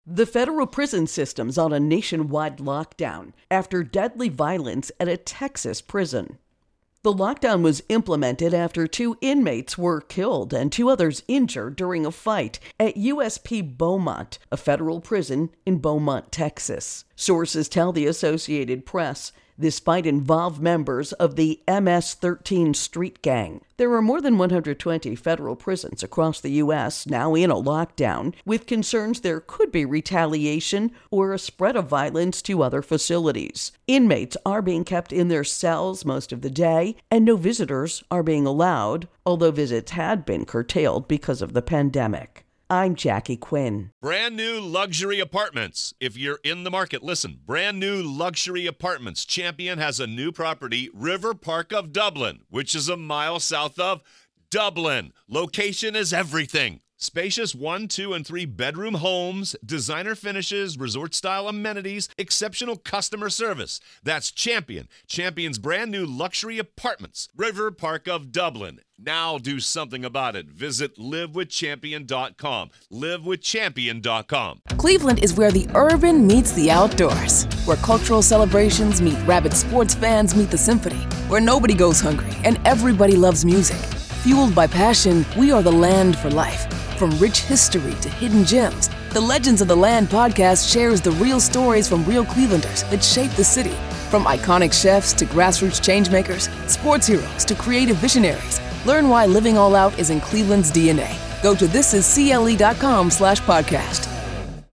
Federal Prisons Intro and Voicer